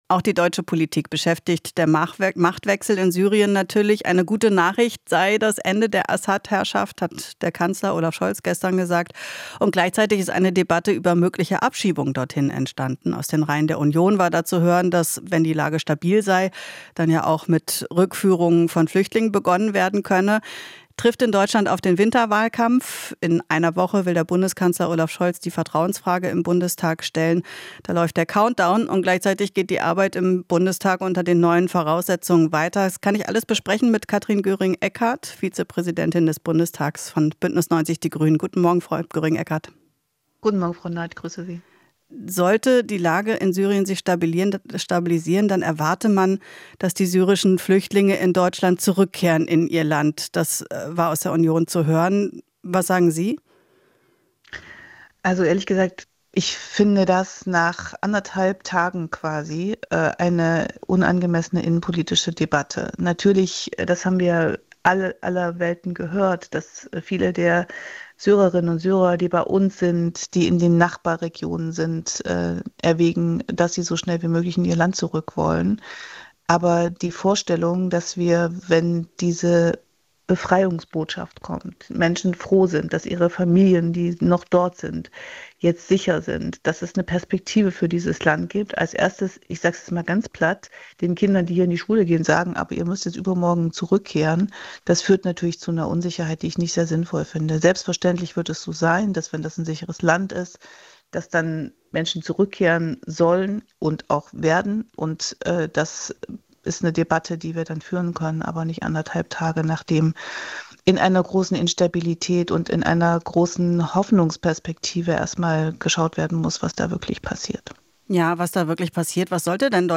Interview - Göring-Eckardt (Grüne) kritisiert Abschiebedebatte um Syrer